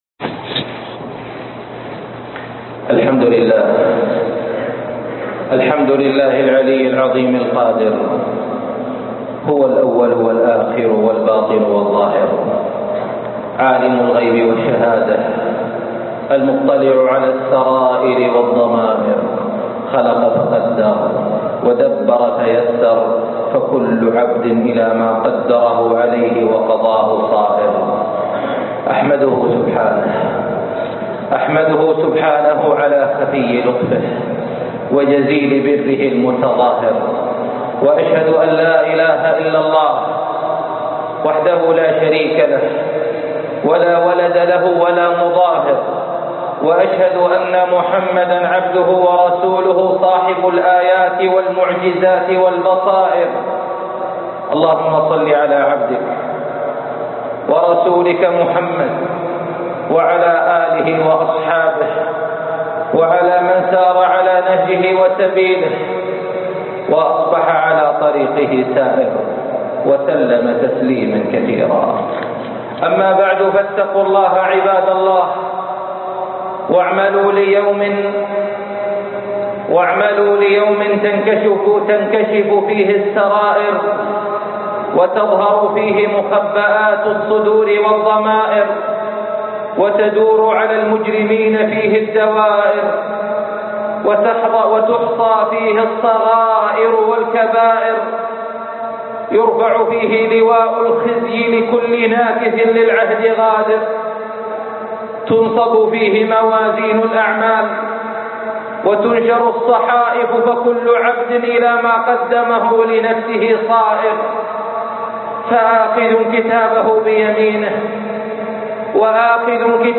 نسائم الفرح - خطب الجمعة